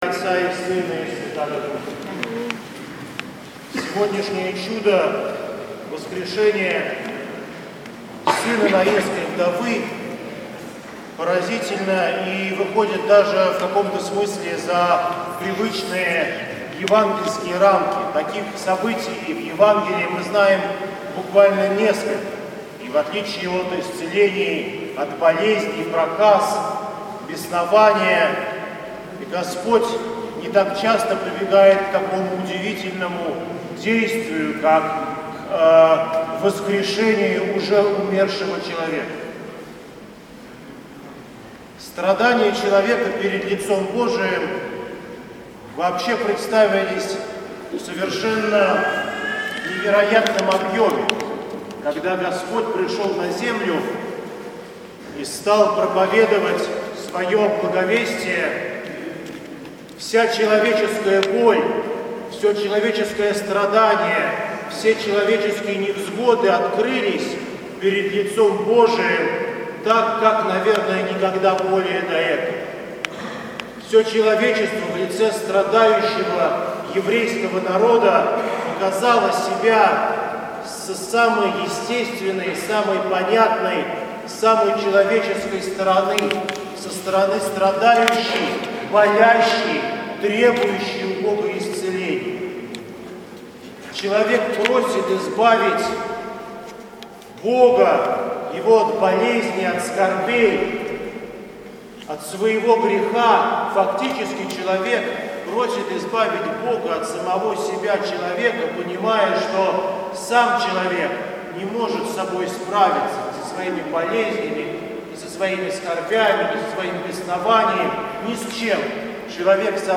поздняя Литургия